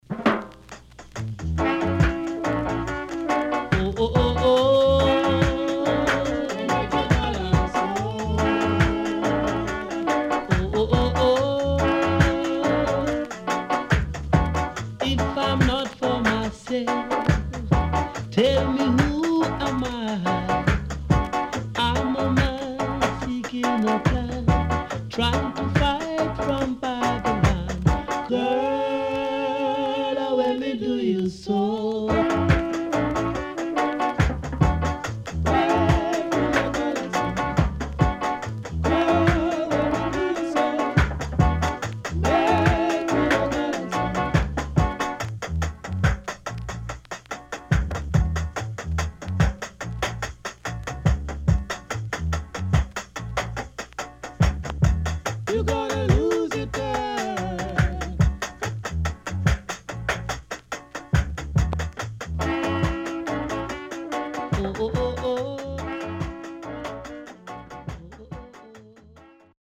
Good Roots & Version
SIDE A:少しチリノイズ入りますが良好です。